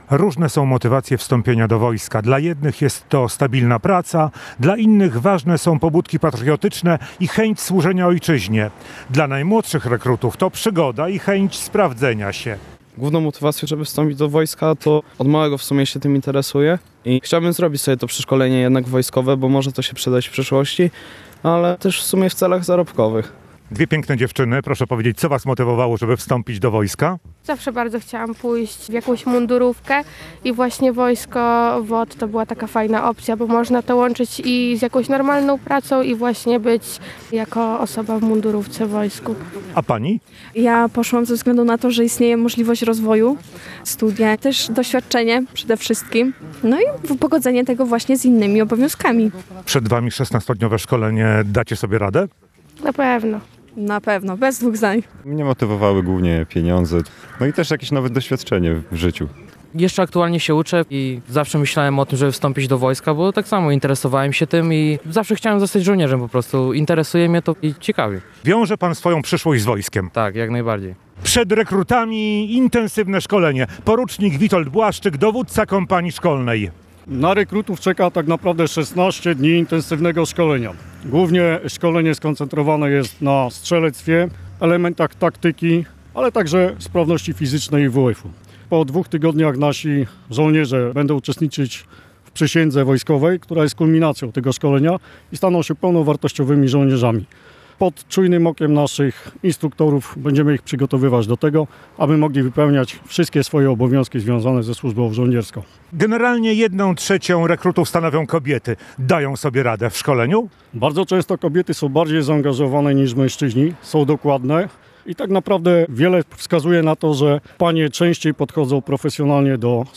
Brygadę Obrony Terytorialnej • Relacje reporterskie • Polskie Radio Rzeszów